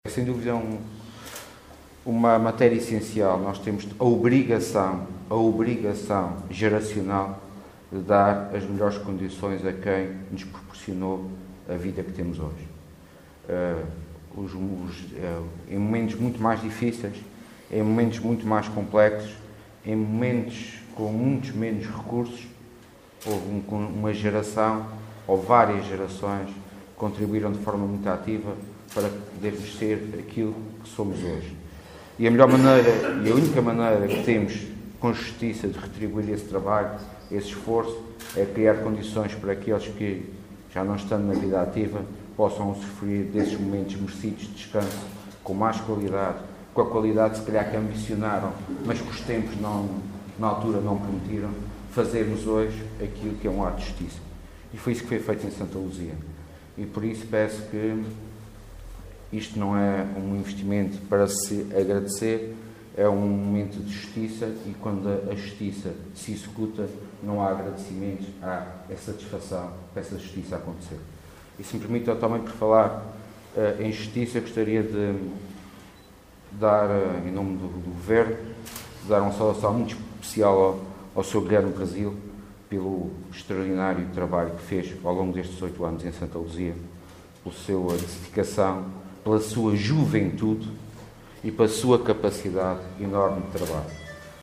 Sérgio Ávila, que falava na cerimónia de inauguração do novo Centro Comunitário de Santa Luzia, uma das cinco freguesias da cidade de Angra do Heroísmo, frisou que a geração dos atuais idosos deu o seu contributo “de forma muito ativa, em alturas muito mais complexas e com muito menos recursos, para sermos o que somos hoje”.